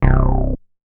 MoogResPlus 002.WAV